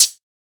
edm-hihat-18.wav